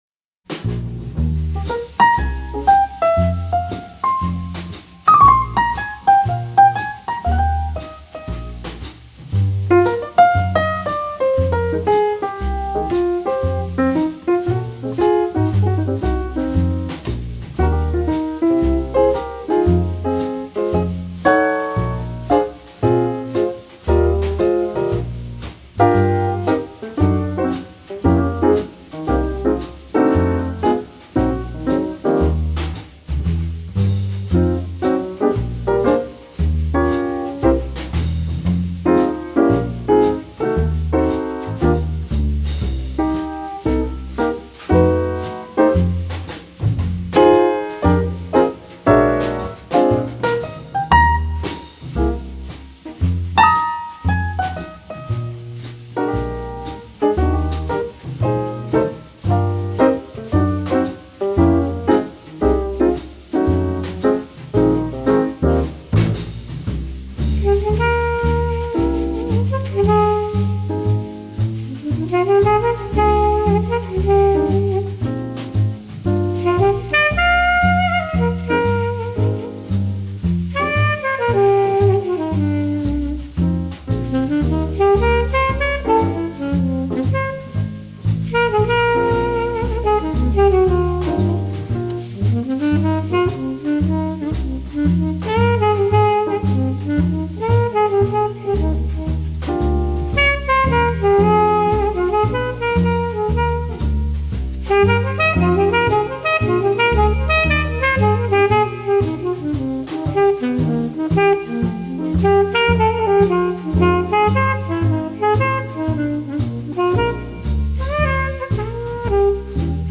piano
alto saxophone
drums
bass
COOL JAZZ